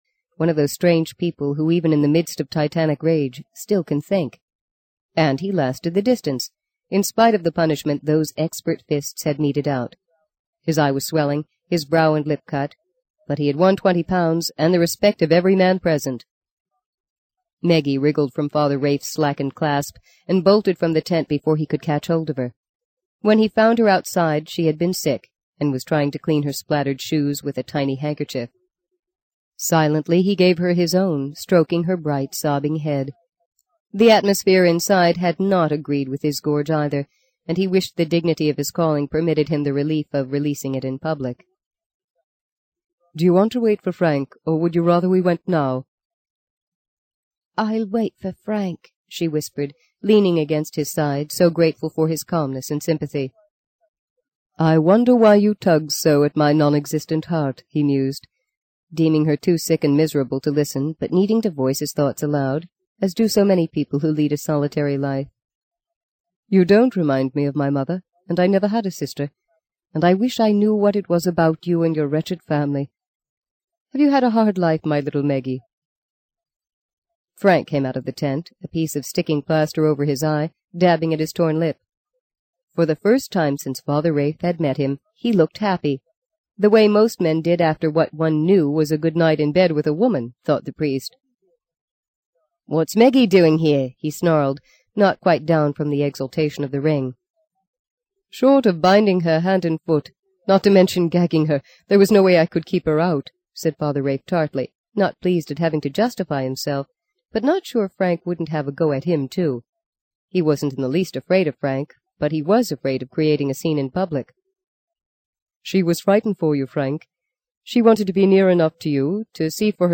在线英语听力室【荆棘鸟】第五章 07的听力文件下载,荆棘鸟—双语有声读物—听力教程—英语听力—在线英语听力室